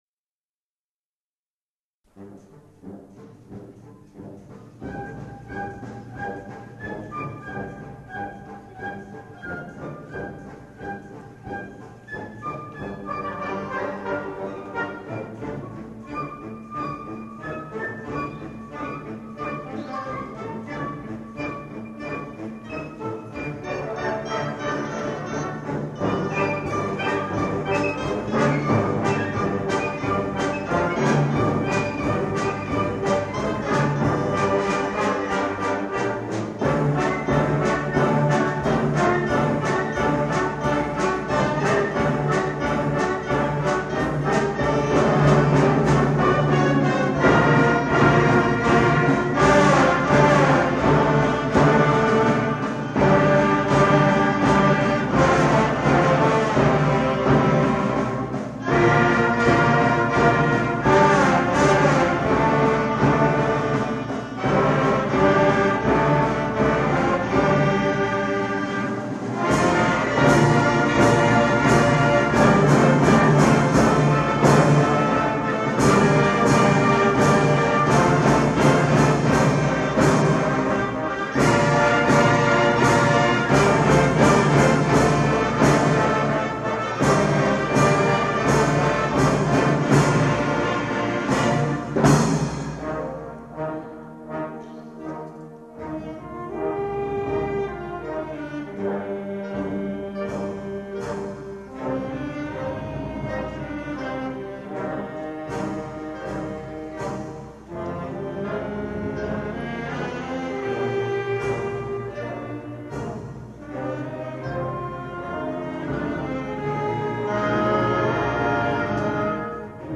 9 janvier 1978 : le concert au grand amphi
Ce concert a été enregistré, ainsi que celui du 2 février salle Jacques Tati à Orsay.